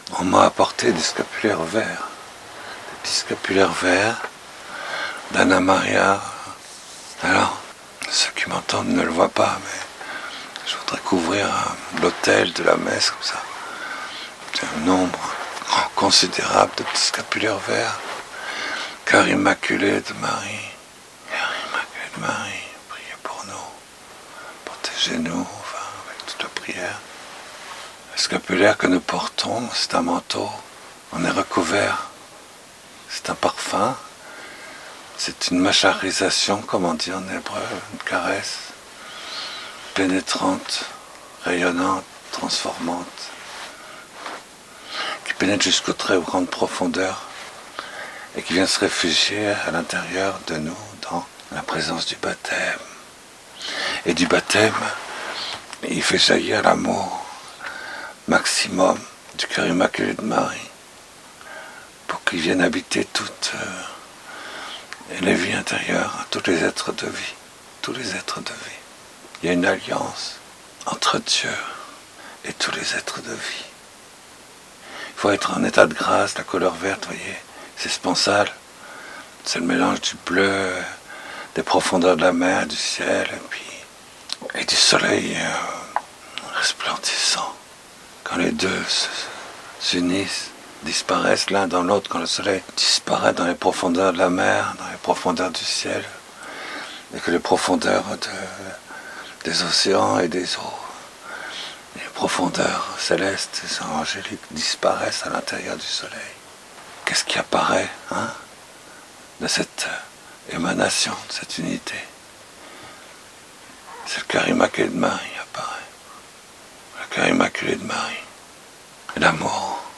18-1Homelie.mp3